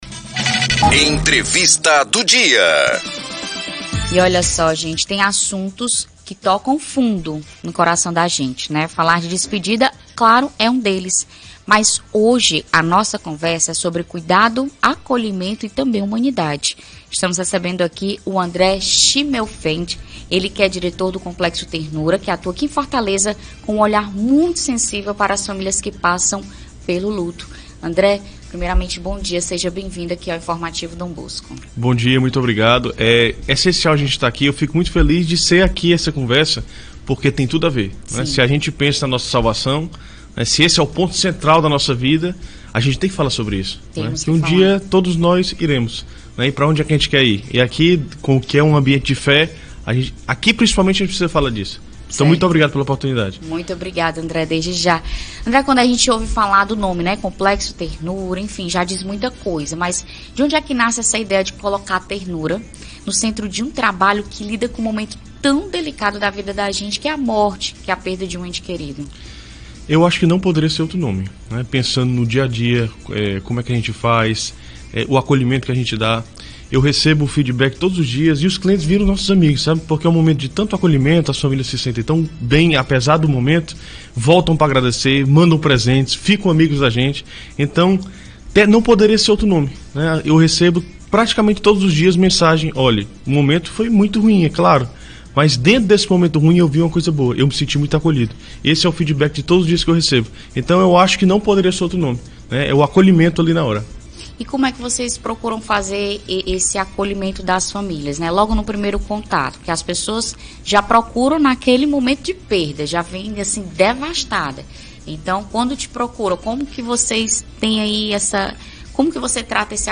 Entrevista do dia